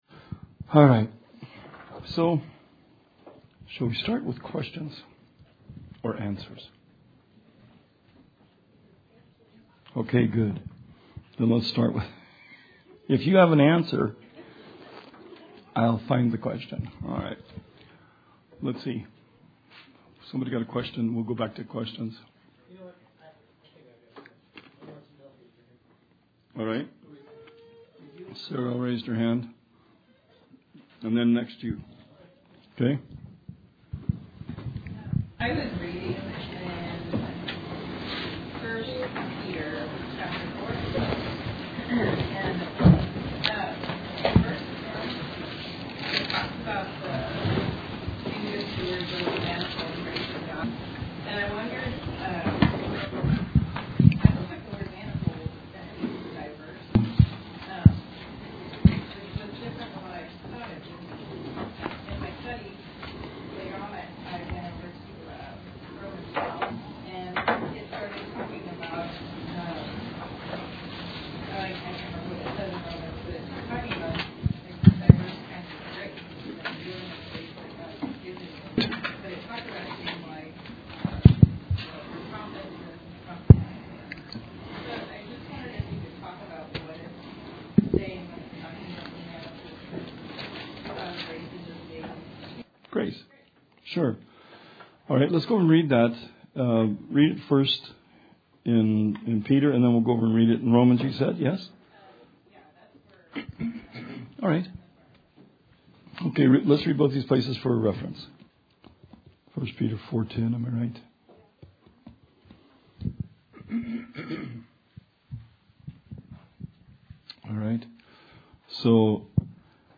Bible Study 1/30/19